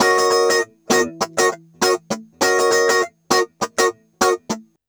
100FUNKY04-L.wav